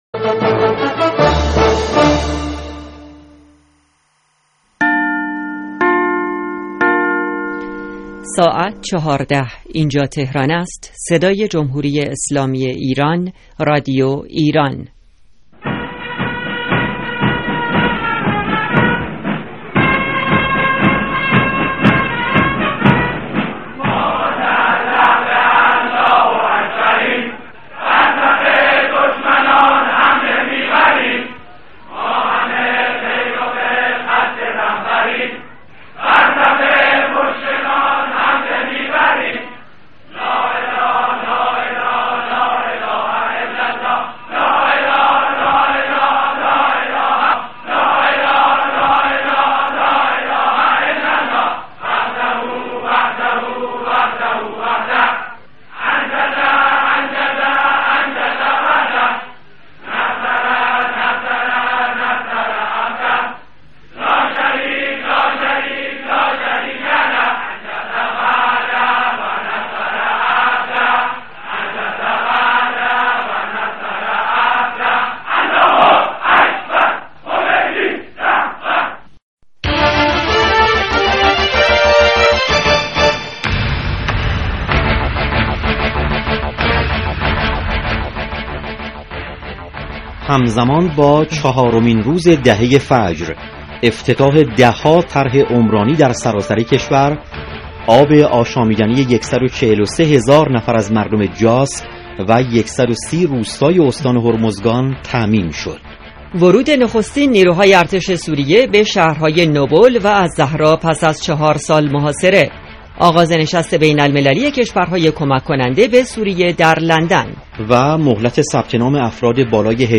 عناوین اخبار 16 بهمن 1394 بسته نیمروزی که شامل سرخط اخبار اقتصادی، سیاسی، خبر های ورزشی و پیک نیمروزی است.